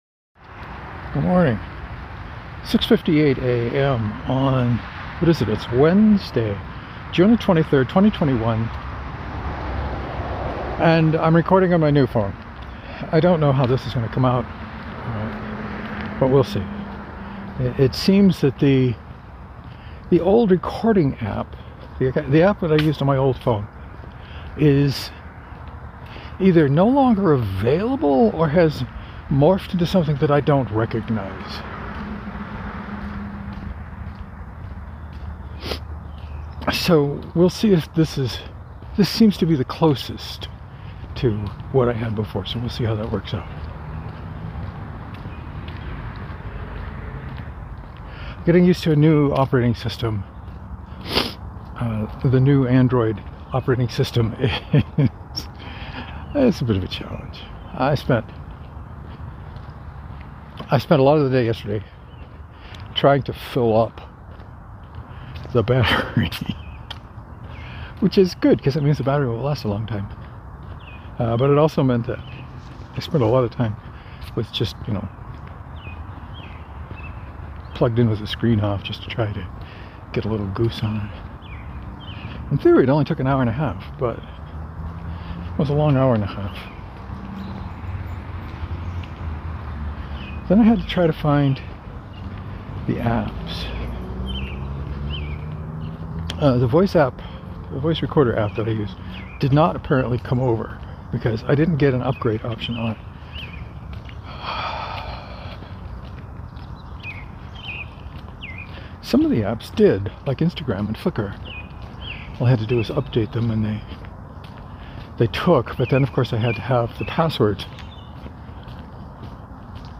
I’m also using new recording software. I may need to find something with a mic boost.